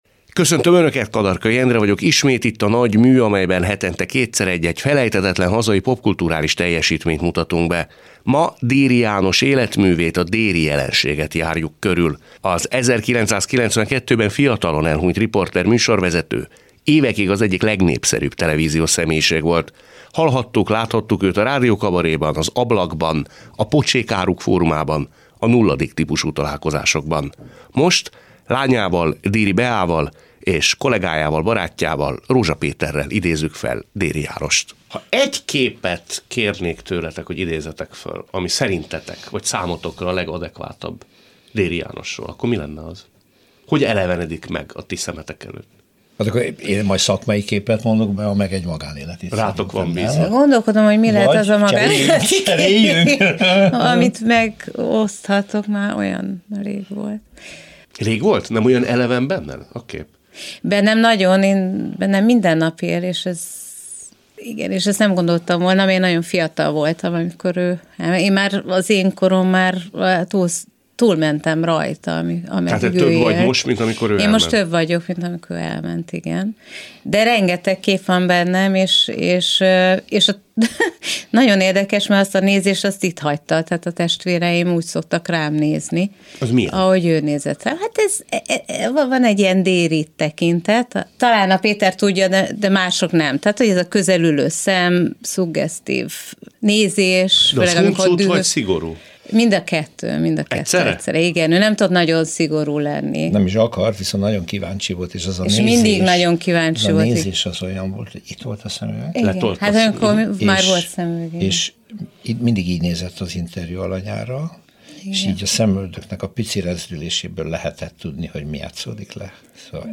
A műsor első felében egy vagy két alkotó mesél a mű eredetéről, olyan szereplőket kérdezünk, akik bábáskodtak az adott alkotás megszületése körül, vagy legalábbis közelről láttak rá a keletkezéstörténetre. A műsor második felében egy külső szem (kritikus, újságíró, kultúrtörténész, vagy szociológus) segít elmagyarázni a sikertörténet társadalmi okait.